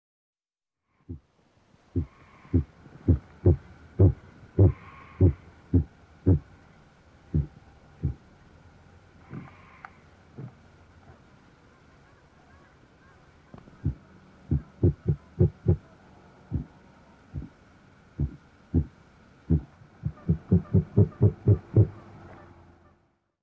4. Cassowary
SouthernCassowary.mp3